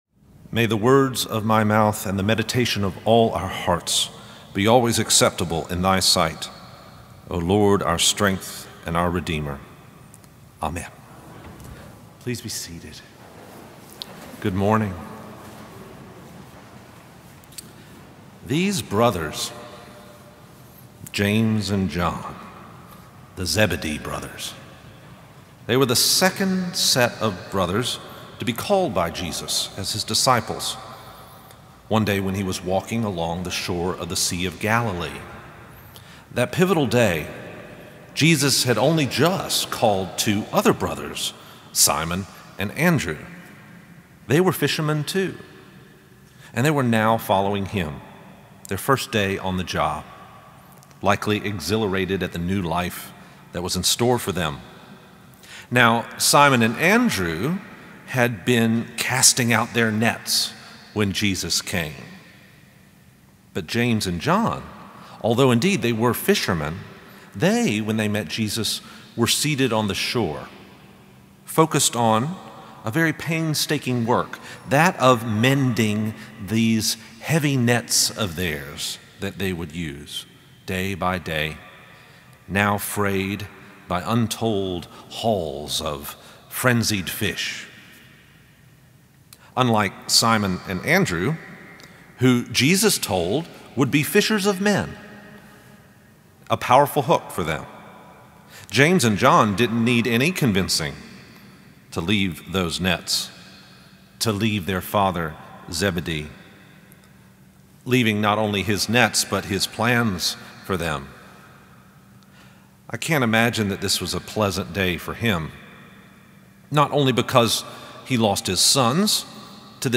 Sermon Audio https